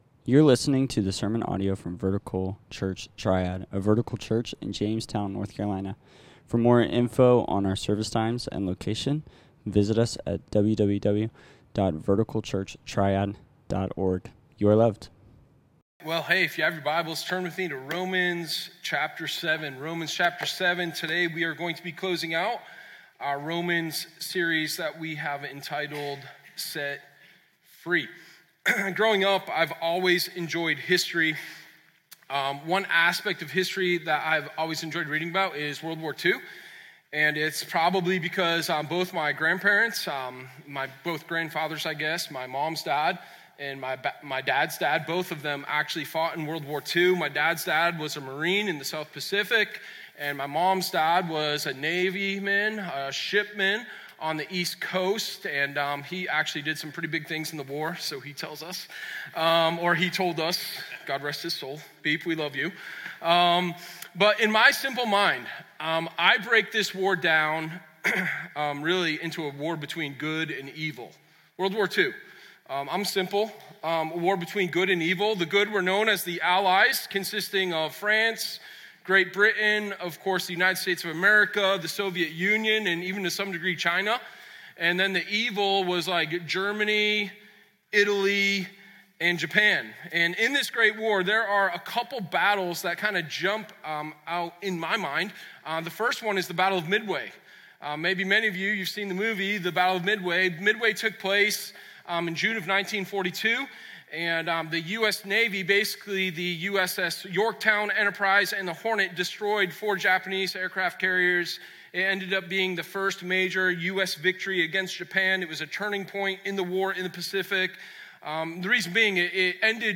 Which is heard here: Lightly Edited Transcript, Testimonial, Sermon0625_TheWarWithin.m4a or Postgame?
Sermon0625_TheWarWithin.m4a